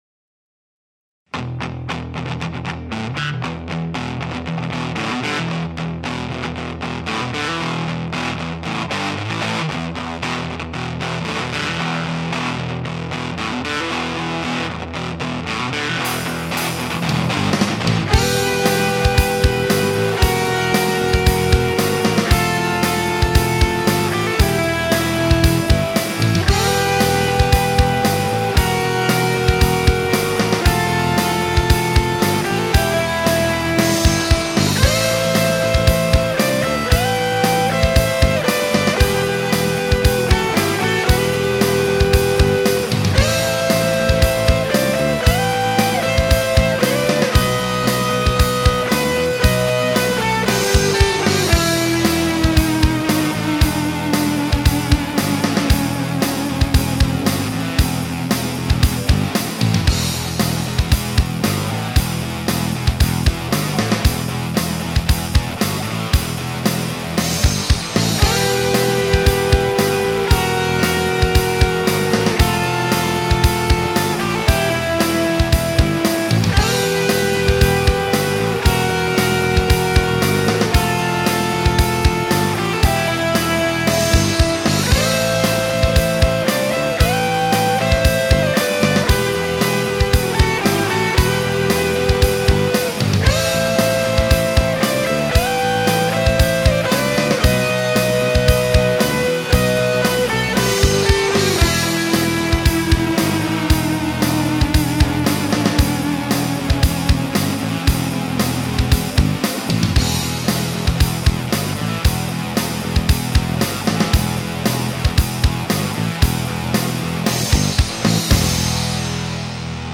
Ukázky filmové hudební tvorby